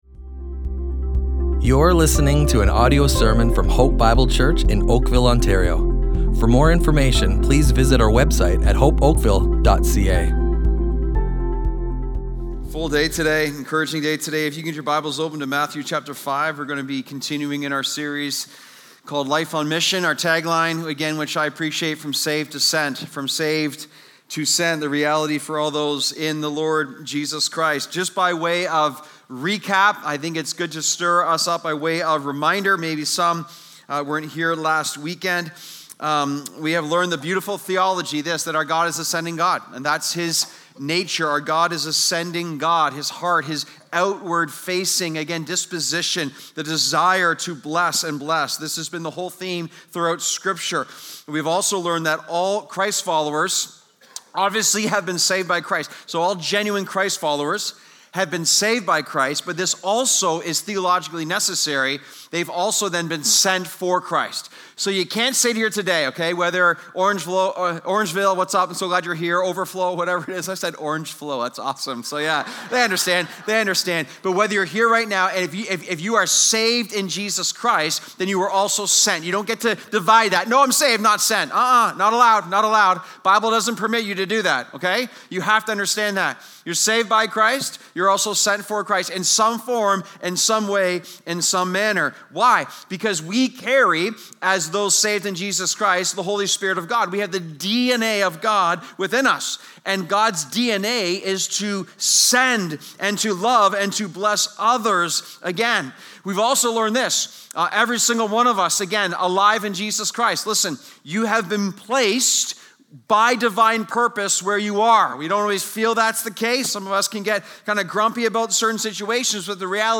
Hope Bible Church Oakville Audio Sermons Life on Mission // Sent as Salt and Light!